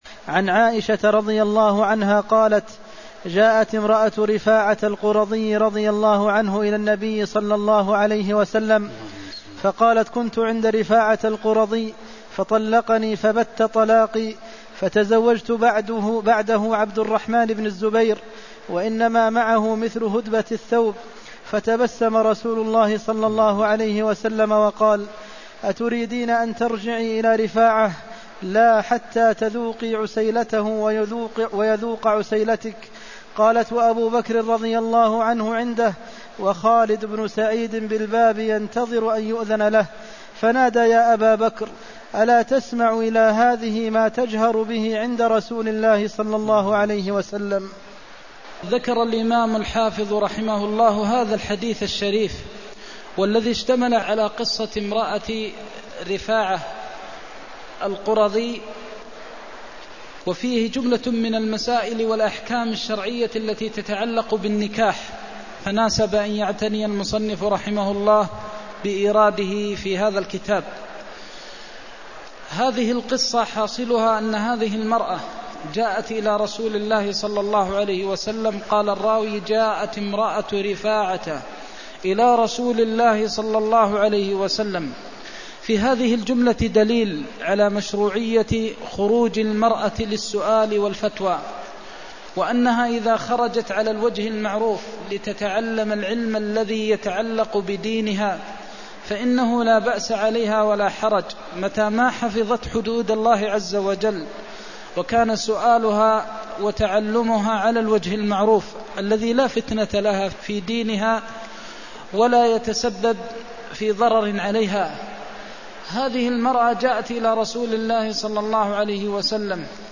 المكان: المسجد النبوي الشيخ: فضيلة الشيخ د. محمد بن محمد المختار فضيلة الشيخ د. محمد بن محمد المختار لا حتى تذوقي عسيلته ويذوق عسيلتك (293)